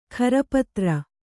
♪ kharapatra